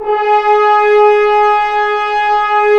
Index of /90_sSampleCDs/Roland L-CD702/VOL-2/BRS_F.Horns 1/BRS_FHns Ambient
BRS F.HRNS0L.wav